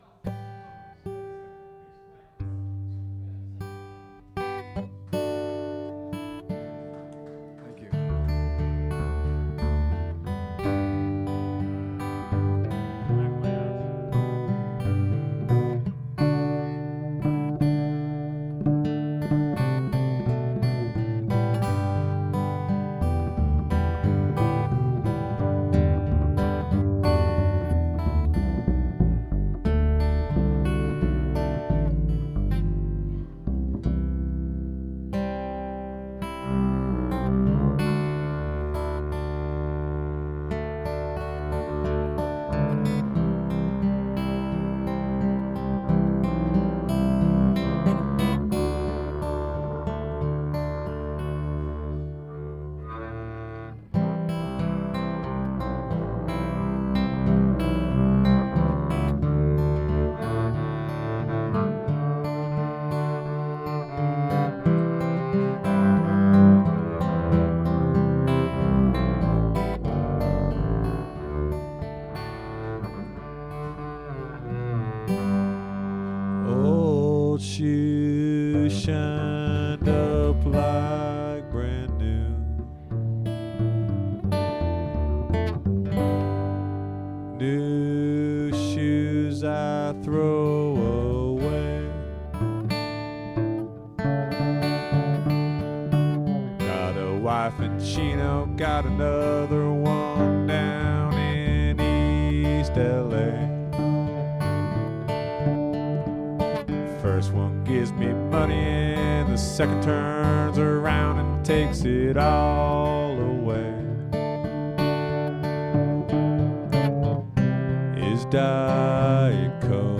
• guitar
• vocal
• string bass
This is a very pretty contrabass-and-guitar art song.